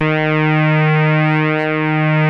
OSCAR D#3 5.wav